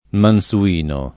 [ man S u- & no ]